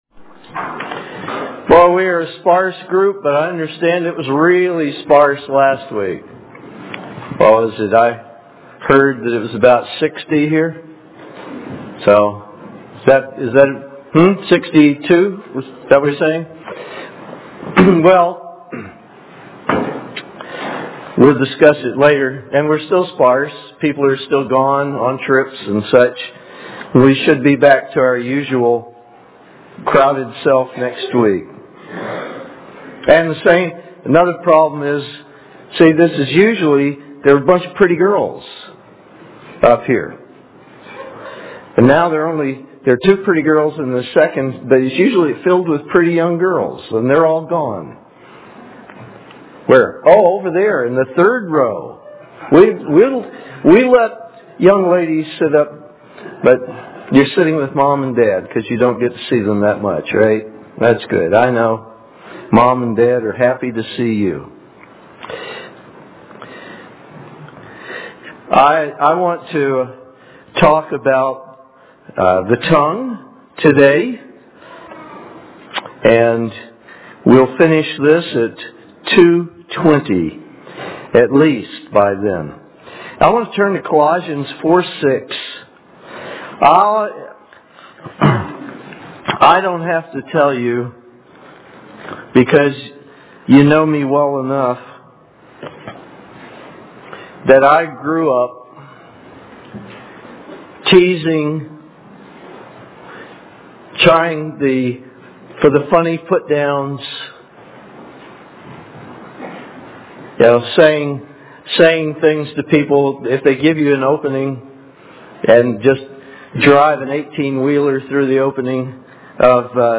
This sermon will give some helps in making our thoughts and our words come out a little sweeter.
Given in Nashville, TN